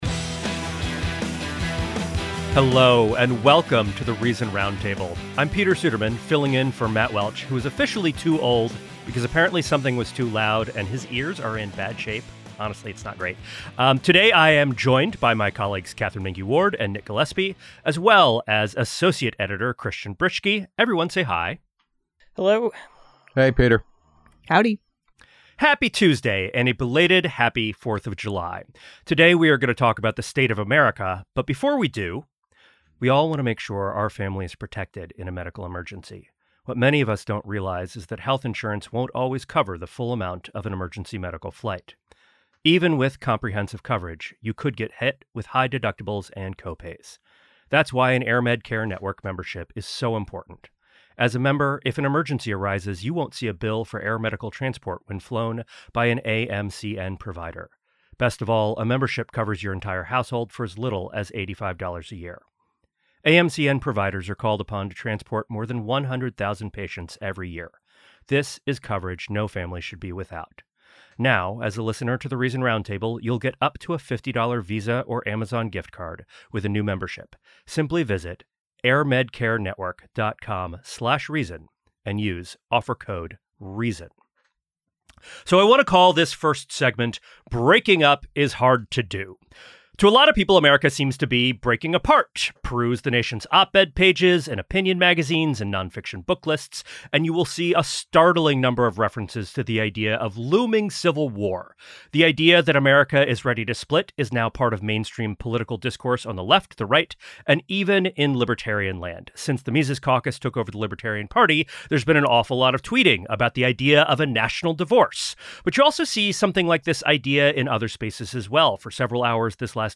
In this week's Fourth of July edition of The Reason Roundtable, editors ruminate on the so-called Disunited States.